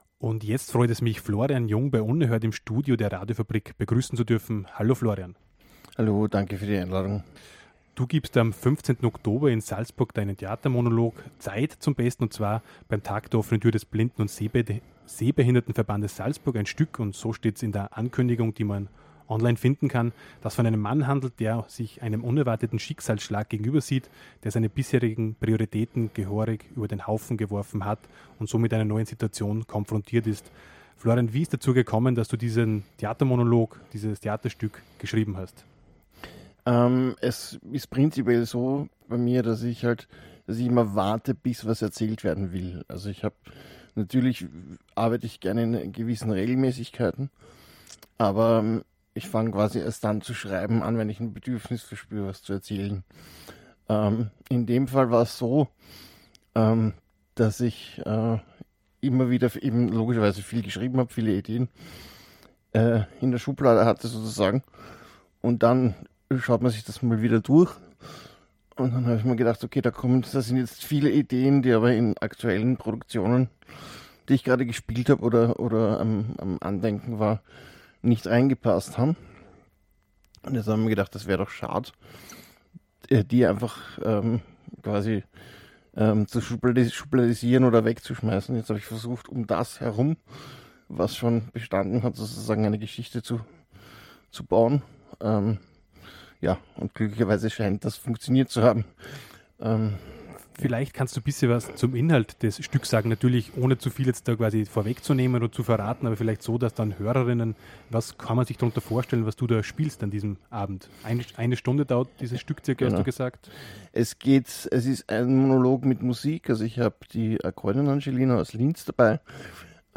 Radiointerview
im Studio der Radiofabrik